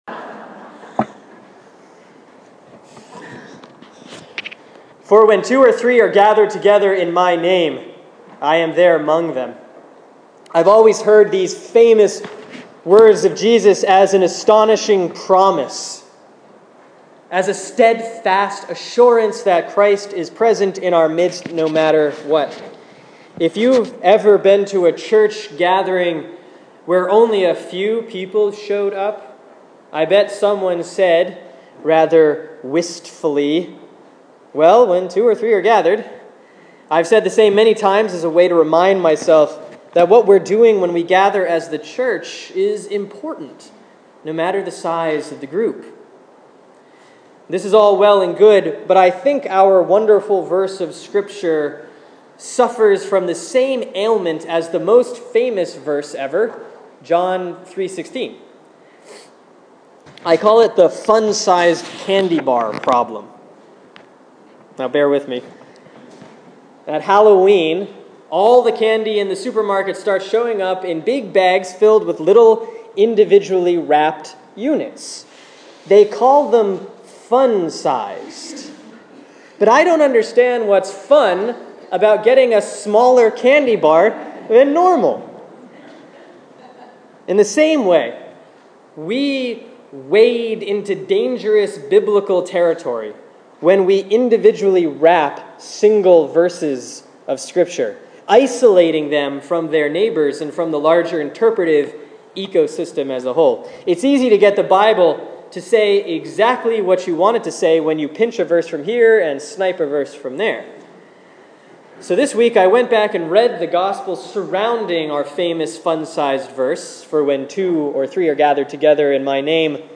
Sermon for Sunday, September 7, 2014 || Proper 18A || Matthew 18:15-20